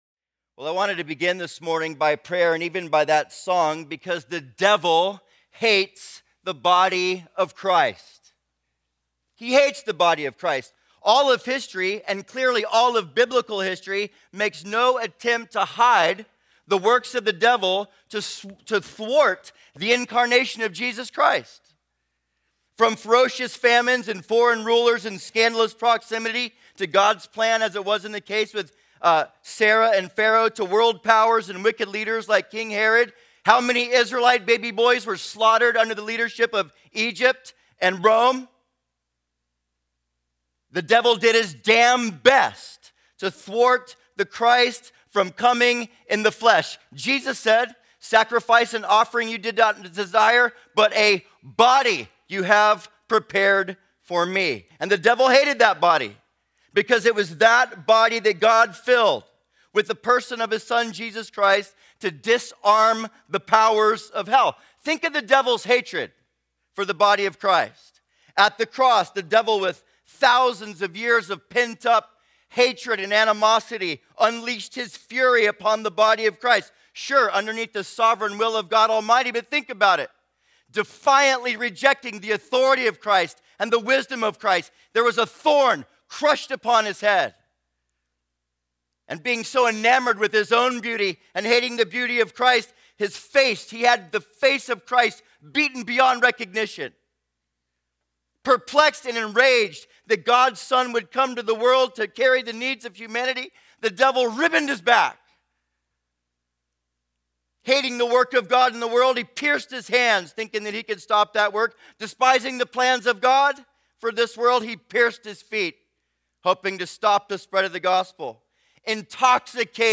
2012 DSPC Conference: Pastors & Leaders Date
2012 Home » Sermons » Session 5 Share Facebook Twitter LinkedIn Email Topics